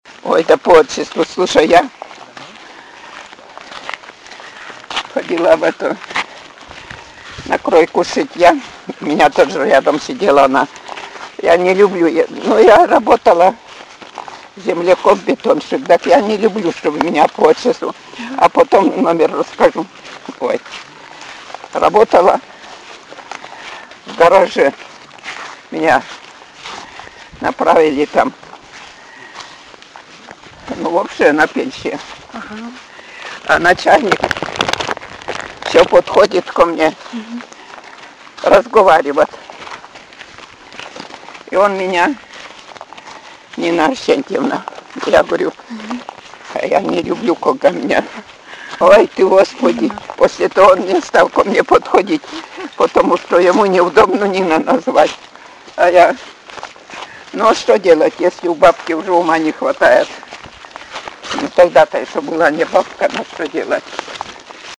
«Ой, да по отчеству…» — Говор северной деревни
Пол информанта: Жен.
Аудио- или видеозапись беседы: